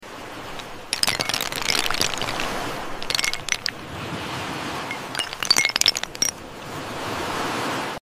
Ice Cub Wave ASMR 🧊🌊 sound effects free download